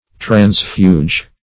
Meaning of transfuge. transfuge synonyms, pronunciation, spelling and more from Free Dictionary.
Search Result for " transfuge" : The Collaborative International Dictionary of English v.0.48: Transfuge \Trans"fuge\, Transfugitive \Trans*fu"gi*tive\, n. [L. transfuga; trans across, over + fugere to flee.]